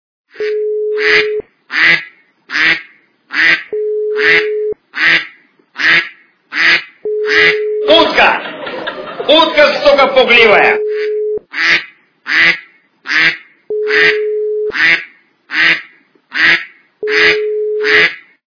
» Звуки » Люди фразы » Комеди Клаб - Утка сука пугливая
При прослушивании Комеди Клаб - Утка сука пугливая качество понижено и присутствуют гудки.